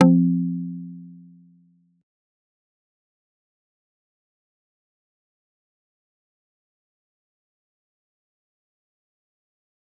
G_Kalimba
G_Kalimba-F3-pp.wav